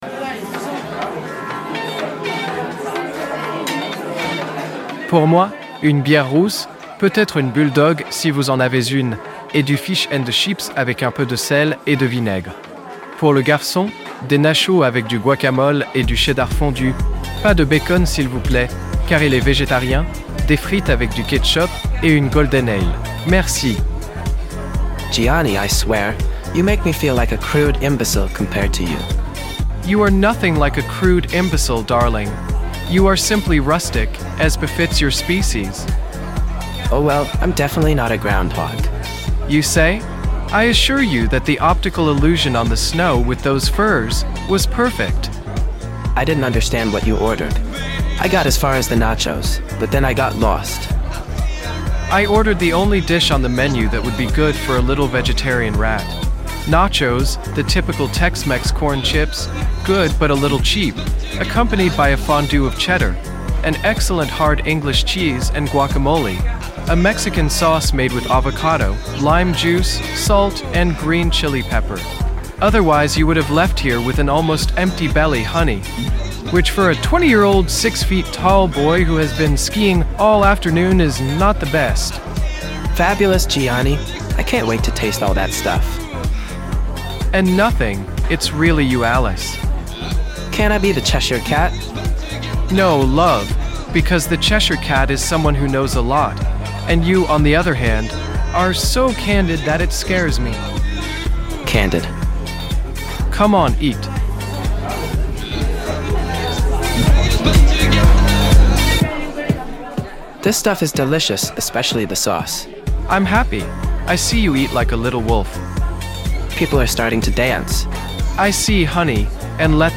During the episode you can listen to songs and covers from "Music Sounds Better With You" (Stardust), "We Can Build A Fire" (Autoheart) and "Back" (Bad Boys Blue).